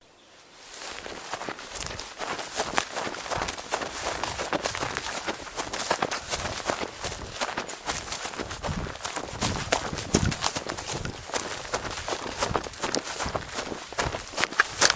在地毯上步行
描述：在地毯上步行，各种速度和力度。
Tag: 步行 脚步 地毯 奔跑